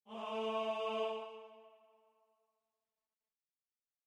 la3_bajo.mp3